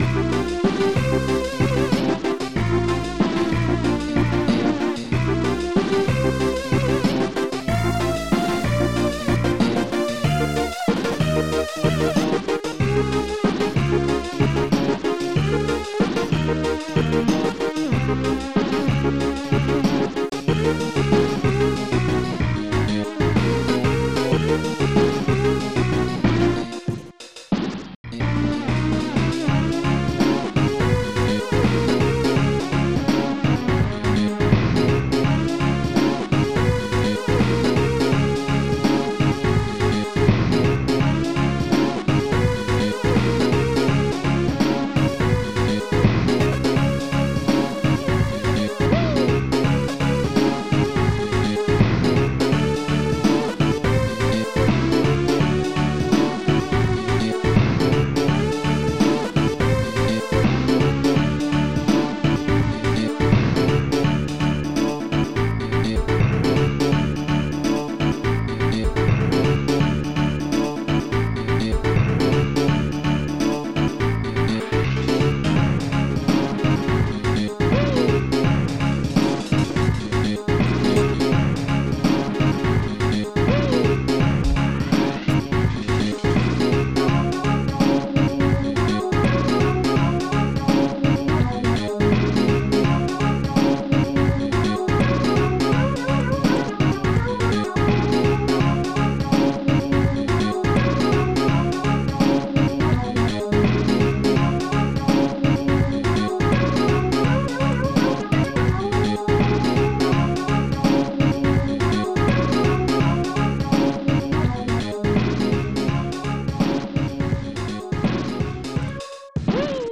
Protracker and family
ST-20:gummi-bdrum
ST-20:gummi-snare
ST-21:new-force.synth